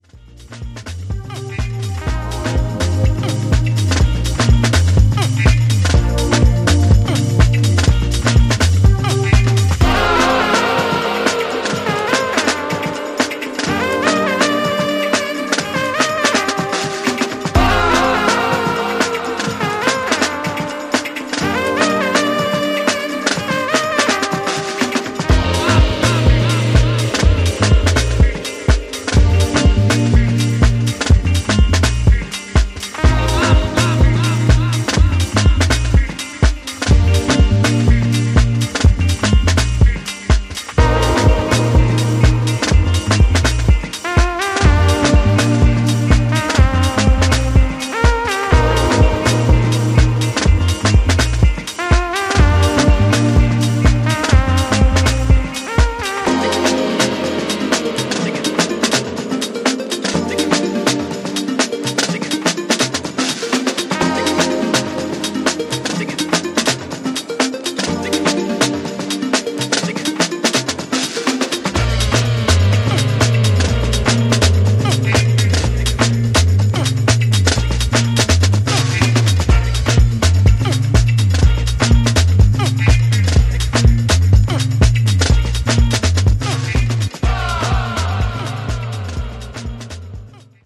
ジャンル(スタイル) DEEP HOUSE / AFRO